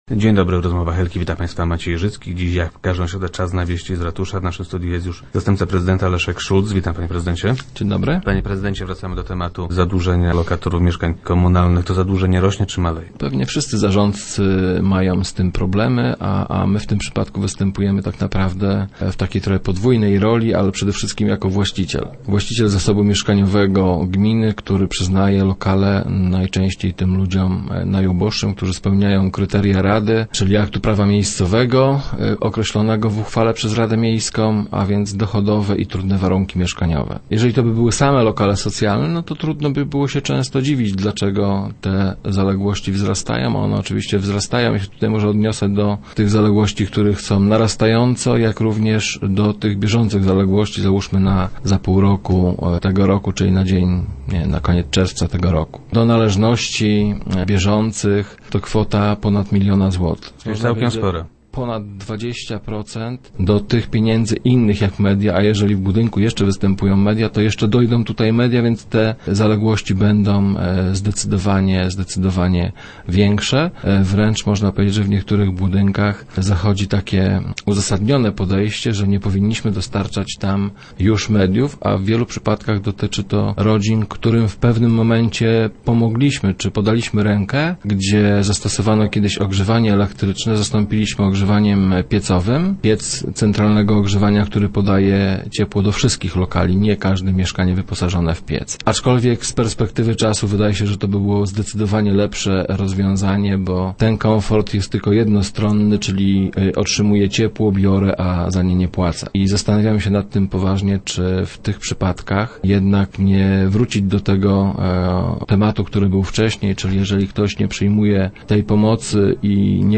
- Dla rodzin, które nie radzą sobie w codziennym życiu, możemy stworzyć wraz z pracownikami MOPPS-u lub z asystentem rodziny plan wydatków. Jednym z jego elementów może być wniosek do prezydenta o nie branie pod uwagę dotychczasowych należności i wyrażenie zgody na zamianę na mniejszy lokal. Oczywiście z uwzględnieniem spłaty zaległości, ale dopiero wówczas, gdy taka rodzina stanie na nogi - mówi wiceprezydent Szulc, który był gościem Rozmów Elki.